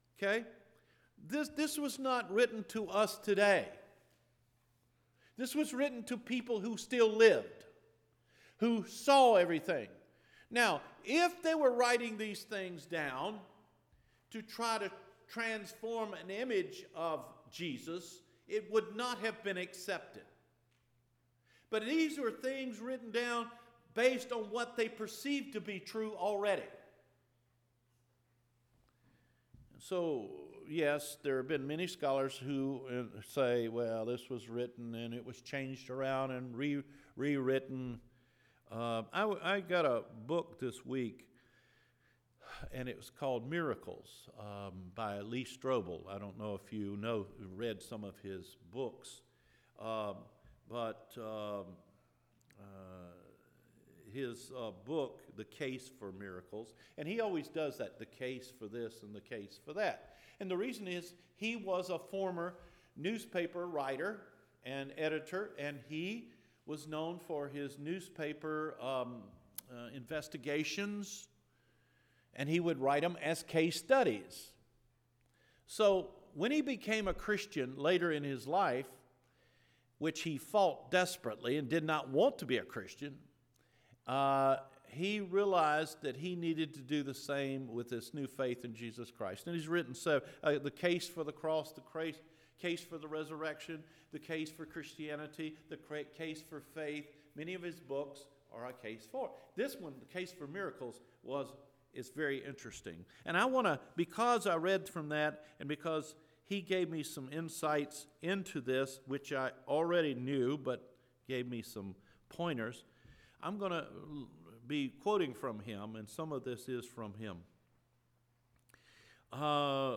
MIRACLES ARE HAPPENING EVERYDAY – APRIL 7 SERMON – Cedar Fork Baptist Church